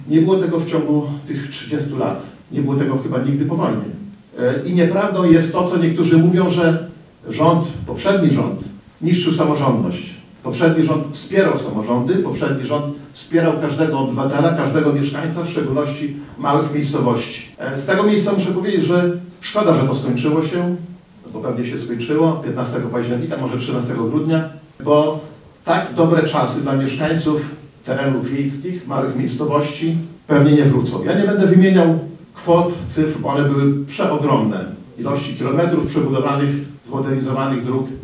W Starostwie Powiatowym w Łomży odbyło się dziś spotkanie opłatkowe.
Starosta łomżyński, Lech Szabłowski wymienił najważniejsze momenty dla powiatu w bieżącym roku. Podkreślił również, że samorząd łomżyński nigdy nie dostał takich możliwości wsparcia finansowego, dzięki któremu mógł zrealizować wiele inwestycji, jak w ciągu ostatnich lat.